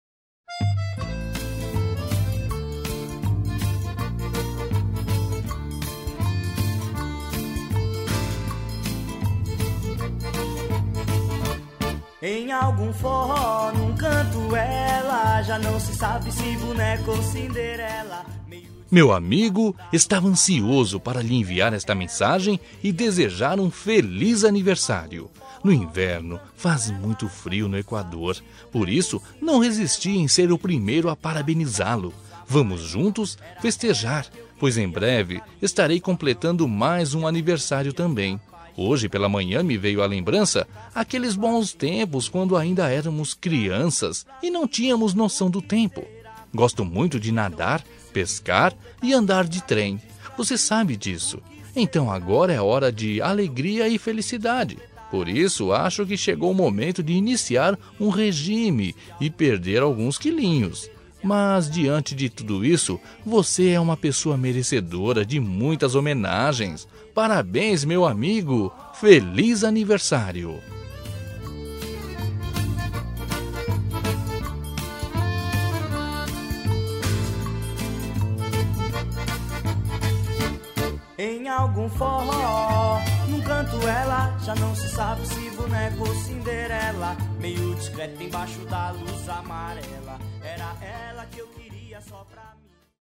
Aniversário de Humor – Voz Masculina- Cód: 200205